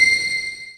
pling.wav